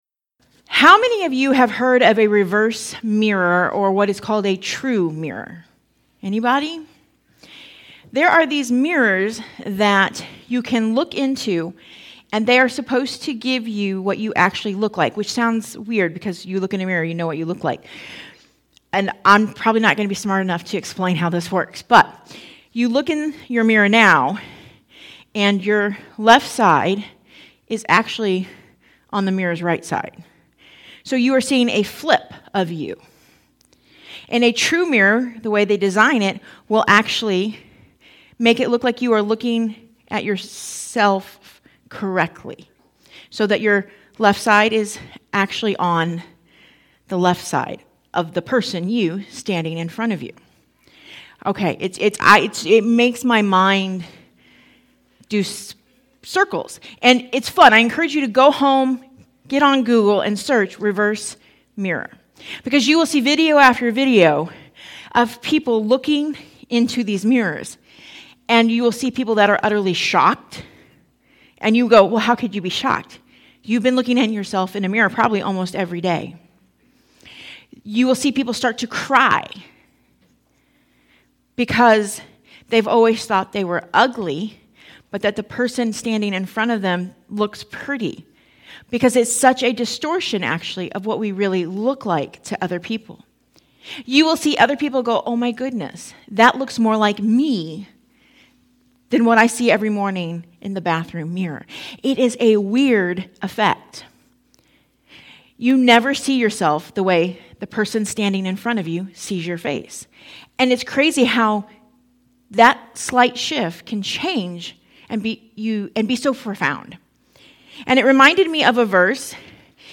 Sermons | Compassion Church